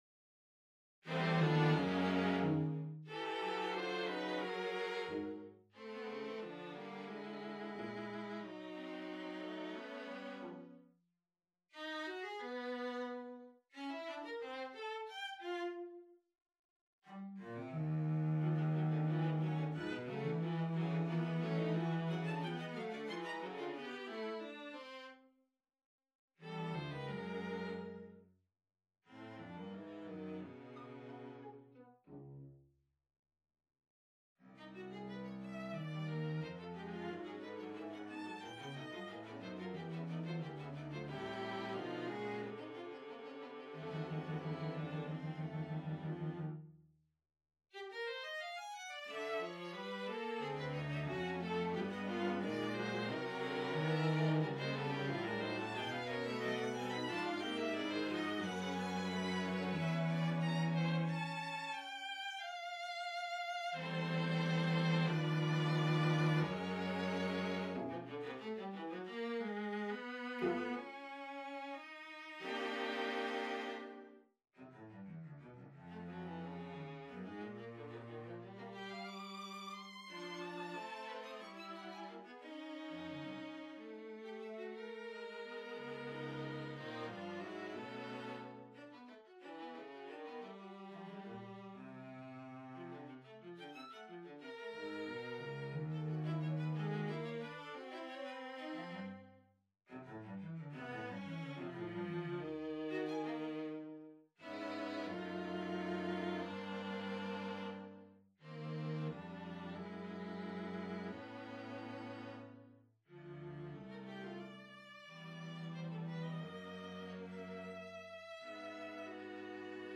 Quintet for Strings No.2 on a purpose-selected tone row Op.42 1. Andante cantabile - Piu mosso - Allegretto con gravit� - Tempo I 2.
Lento molto 4. Presto molto - Piu mosso - Prestissimo Date Duration Download 11 June 2013 35'10" Realization (.MP3) Score (.PDF) 48.2 MB 872 KB